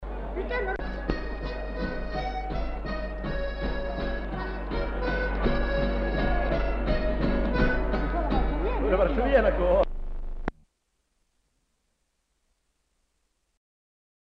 Lieu : Samatan
Genre : morceau instrumental
Instrument de musique : accordéon diatonique ; guitare
Danse : varsovienne
Notes consultables : Le joueur de guitare n'est pas identifié.